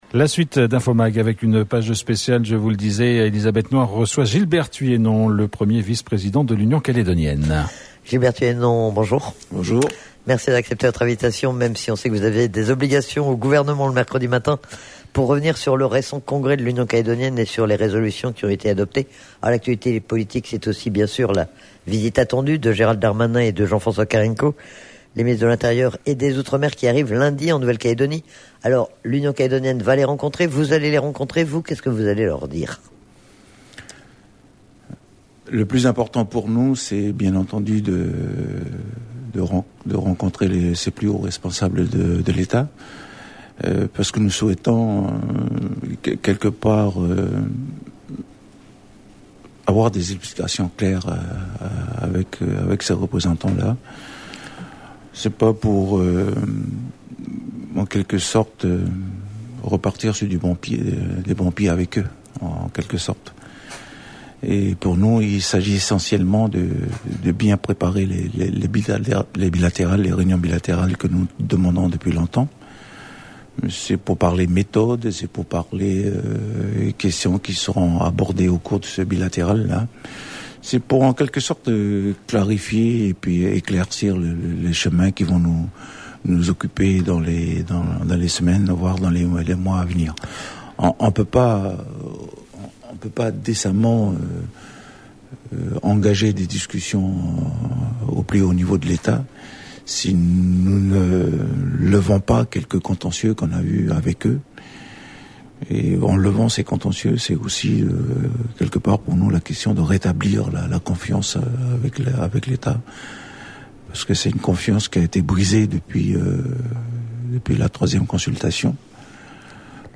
Actualité politique ce midi sur RRB. Gilbert Tyuienon, le 1er vice-président de l'Union calédonienne était notre invité dans une page spéciale pour revenir sur le récent congrès de l'Union calédonienne et les résolutions qui ont été adoptées. Il était aussi interrogé sur la prochaine visite de Gérald Darmanin et de Jean-François Carenco qui sont attendus lundi en Nouvelle-Calédonie.